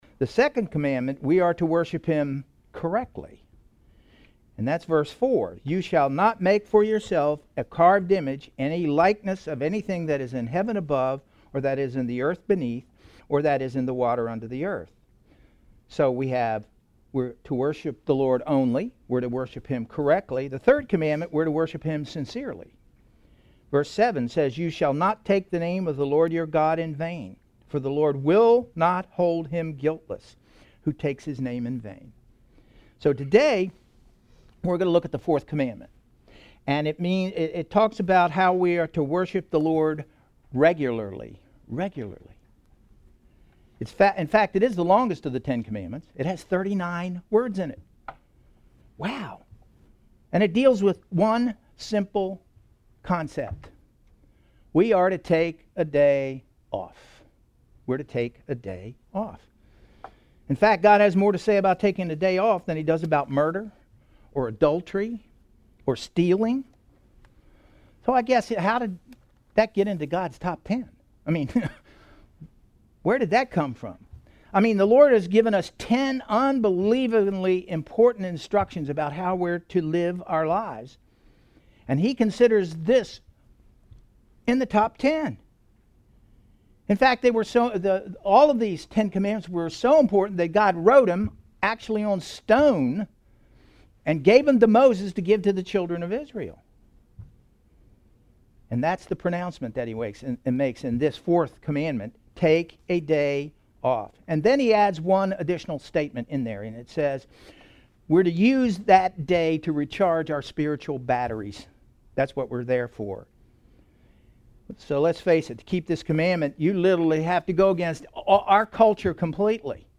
Exodus 20:8-11 Service Type: Sunday Morning Worship « Hallowed be Thy Name(Exodus 20:7